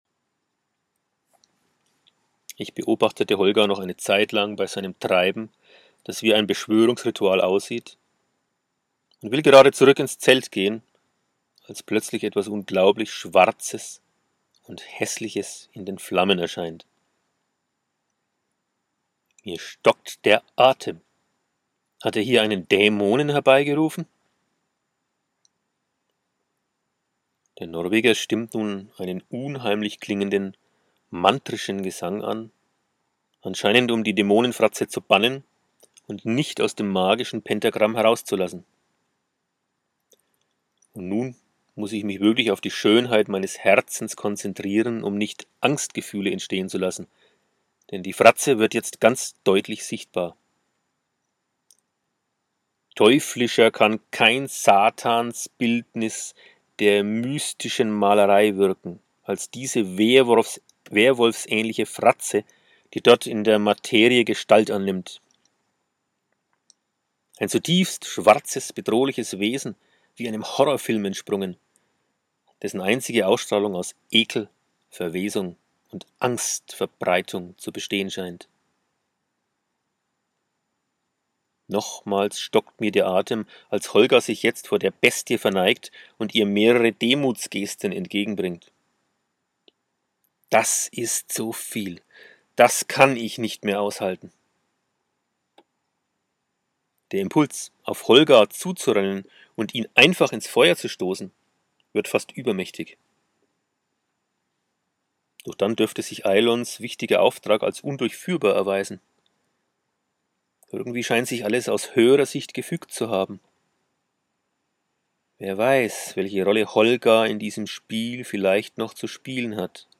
Das Magische Tor - Parzzival - Hörbuch